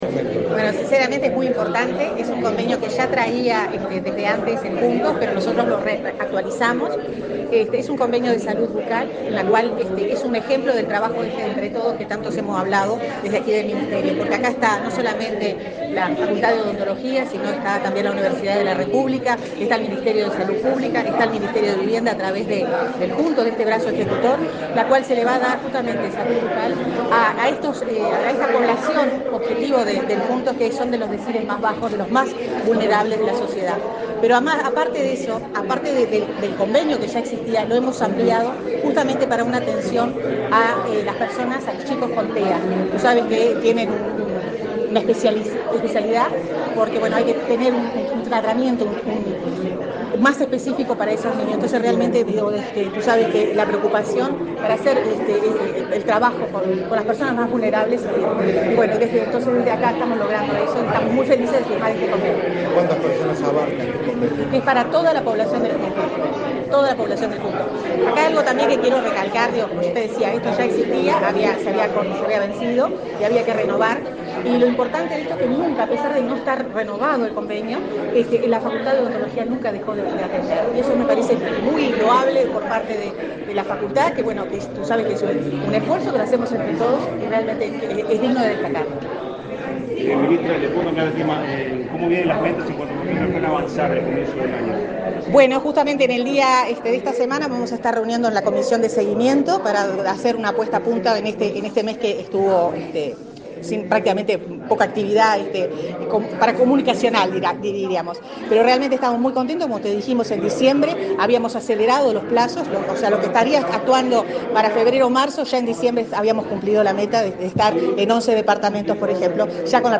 Declaraciones a la prensa de la ministra de Vivienda y Ordenamiento Territorial, Irene Moreira